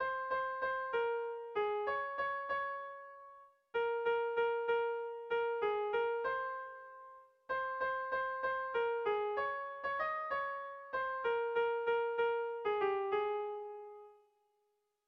Melodías de bertsos - Ver ficha   Más información sobre esta sección
Tragikoa
8A / 8A / 10 / 8A (hg) | 8A / 8A / 18A (ip)
ABD